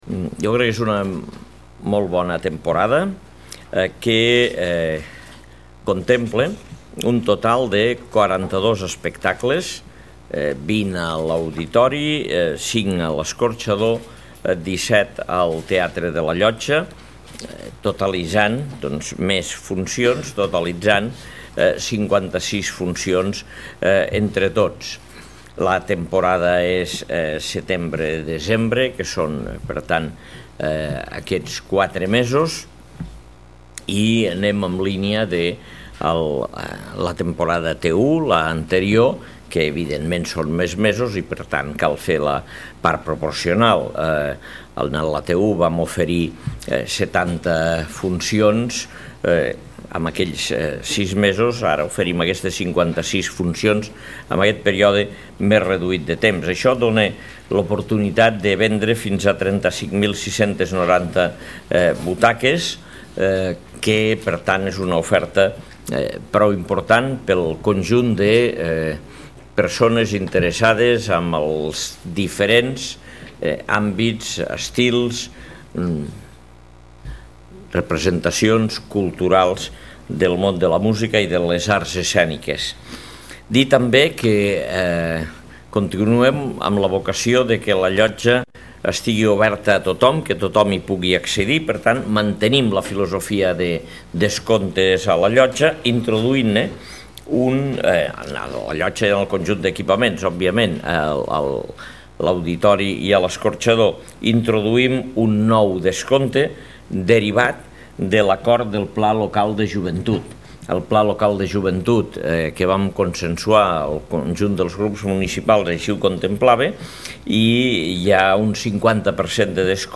tall-de-veu-de-lalcalde-angel-ros-sobre-la-nova-programacio-de-la-t2-latemporada-tardor-2017